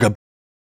Mouth Interface (6).wav